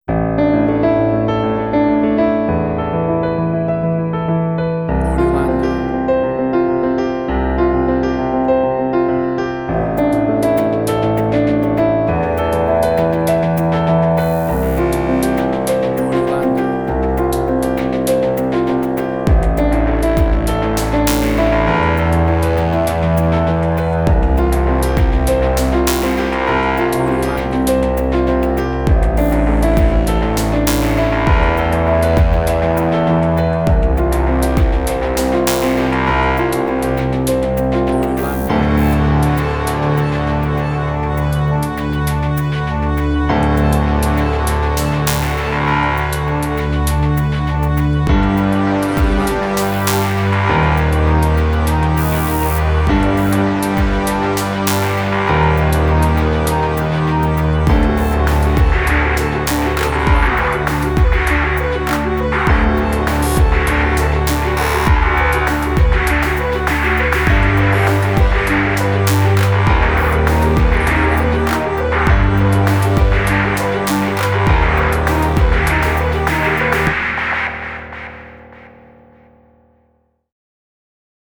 IDM, Glitch.
emotional music
Tempo (BPM): 100